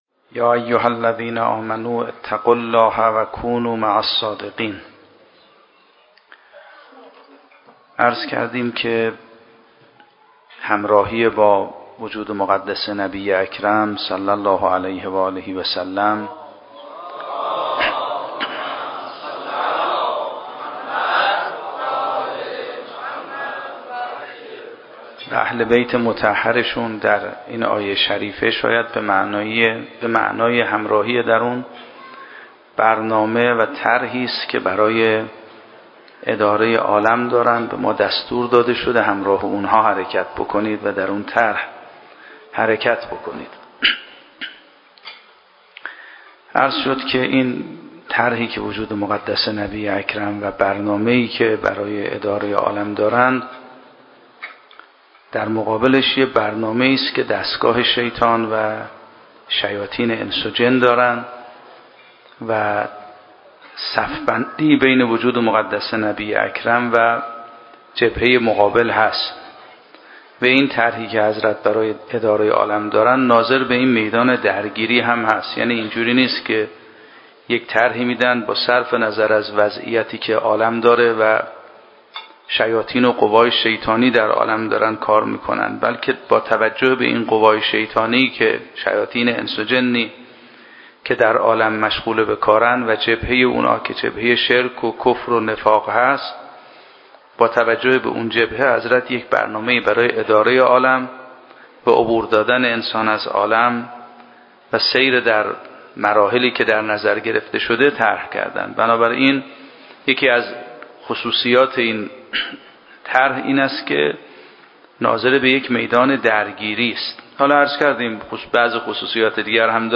شب چهارم محرم 96 - هیئت ثار الله - سخنرانی